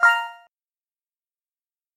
Звуки загрузки файла